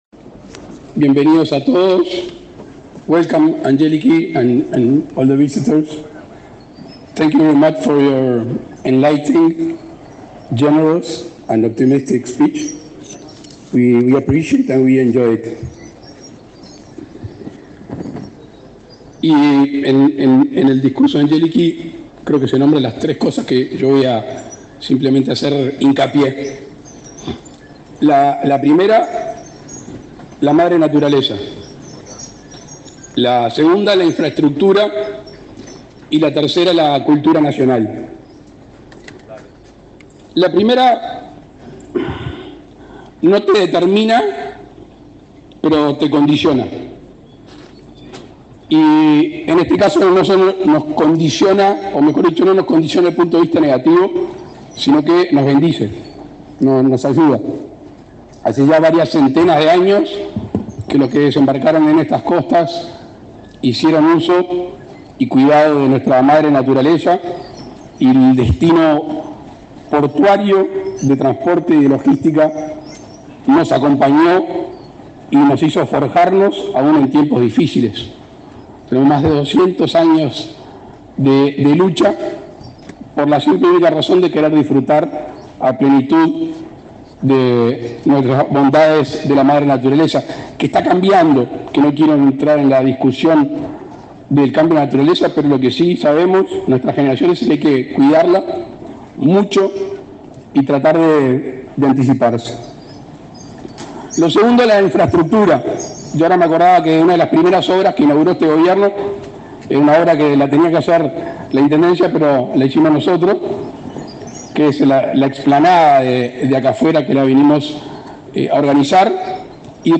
Palabras del presidente Luis Lacalle Pou
Palabras del presidente Luis Lacalle Pou 12/04/2024 Compartir Facebook Twitter Copiar enlace WhatsApp LinkedIn El presidente de la República, Luis Lacalle Pou, participó, este viernes 12 en el puerto de Nueva Palmira, en la inauguración de una terminal de líquidos del Grupo Navíos.